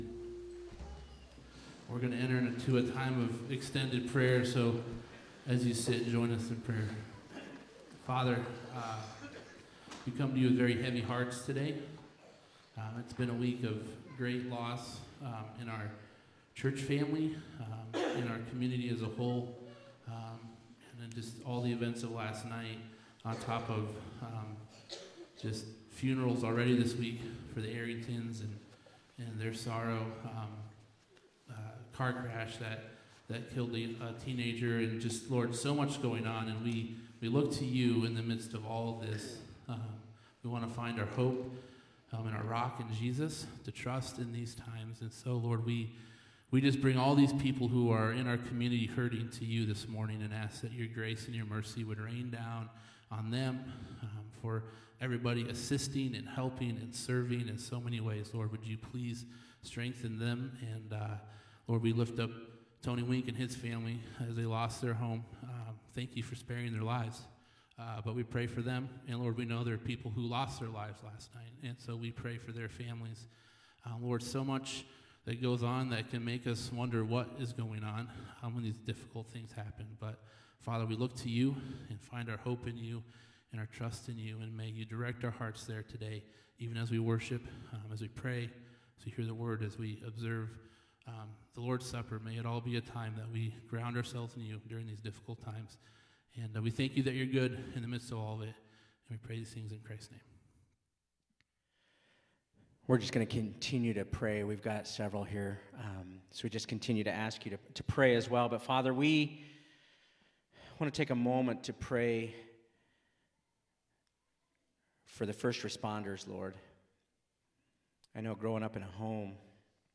Responding to Tragedy – Prayer Service
This is a prayer service after the EF4 tornado that struck the Winterset community.